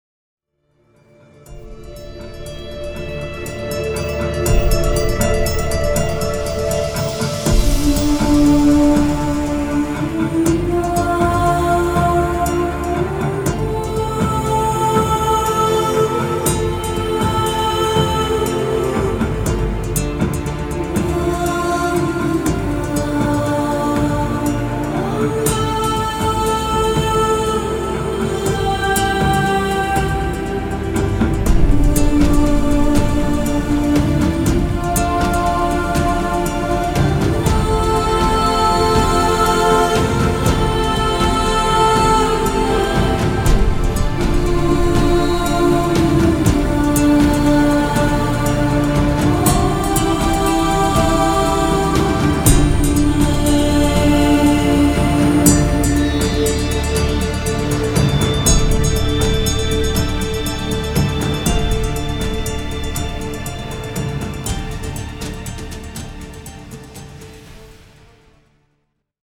the romantic and otherworldly cinematic piece